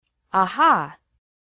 Звуки человека, аха
• Качество: высокое
Звук аха девушки